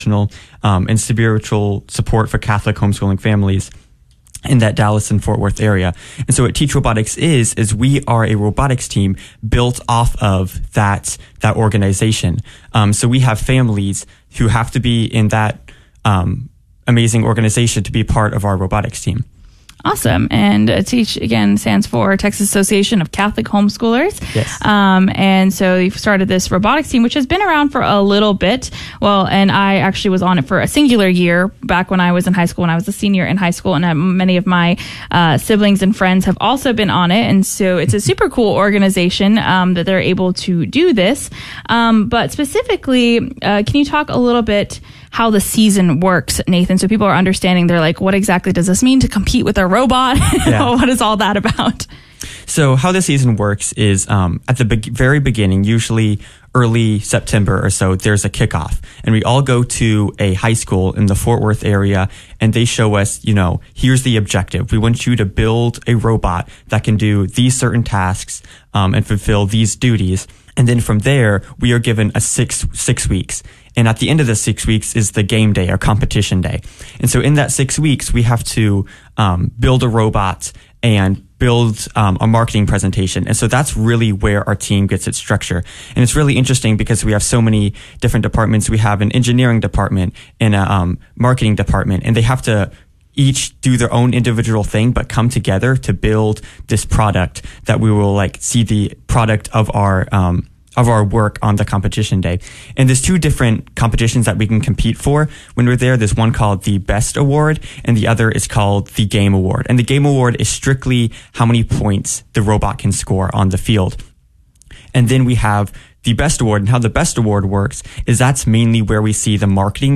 KATH Interview of the Week